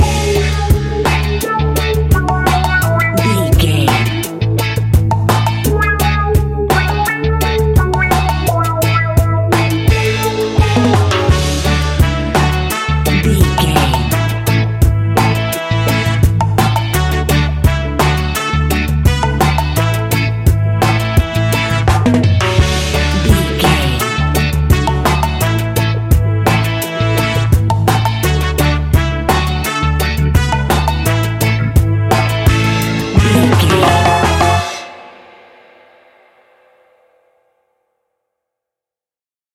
Classic reggae music with that skank bounce reggae feeling.
Uplifting
Aeolian/Minor
F#
reggae
dub
laid back
off beat
drums
skank guitar
hammond organ
percussion
horns